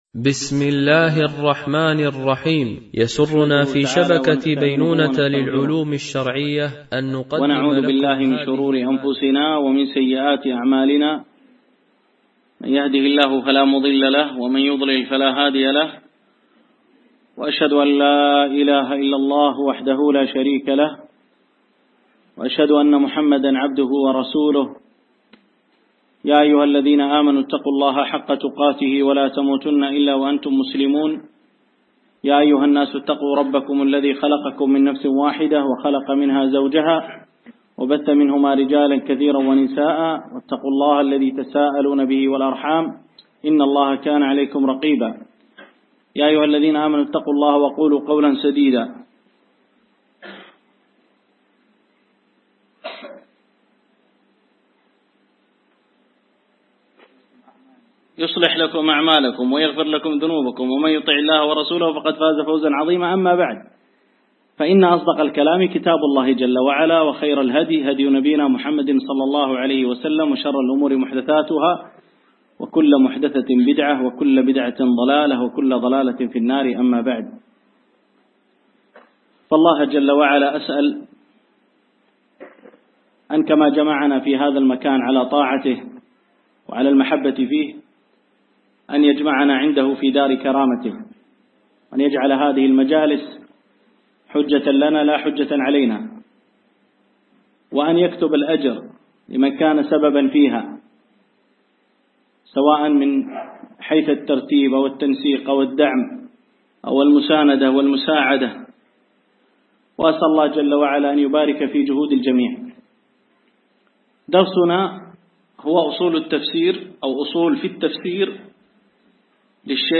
شرح أصول التفسير ـ الدرس الأول